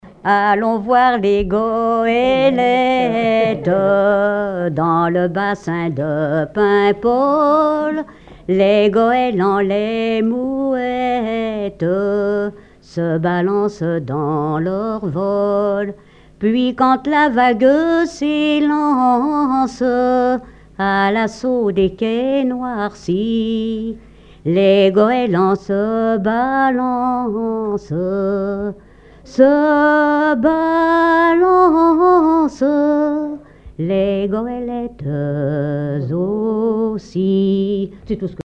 Genre strophique
collecte en Vendée
Pièce musicale inédite